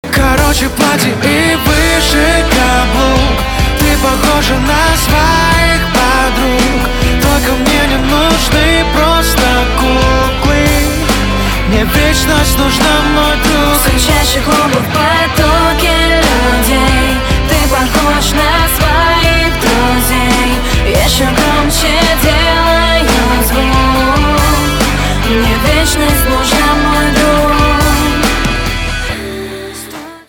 • Качество: 320, Stereo
поп
красивые